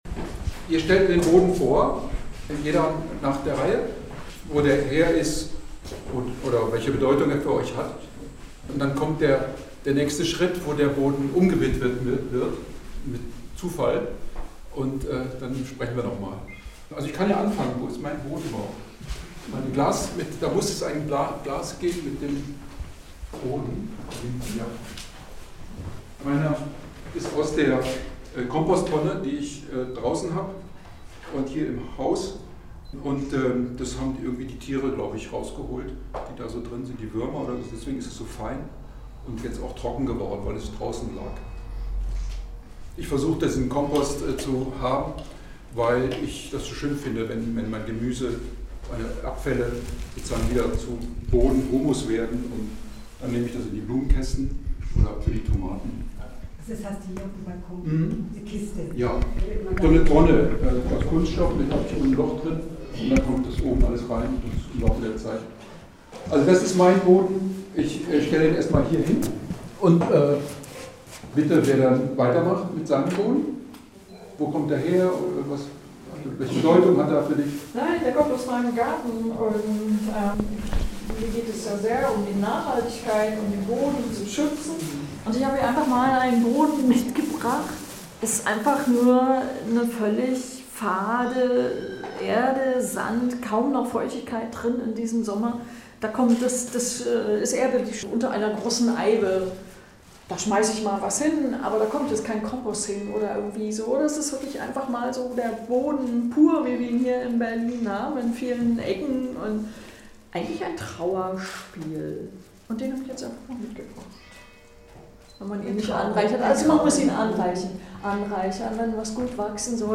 Es war eine Mischung aus Performance, Künstlergespräch, Gedankenaustausch und Umweltanalyse:
Künstler und Besucher brachten jeweils eine Hand voll Erde mit in den Projektraum der Group Global 3000. Dort sprachen sie darüber, woher ihr Boden kam und was sie mit der mitgebrachten Erde assoziieren.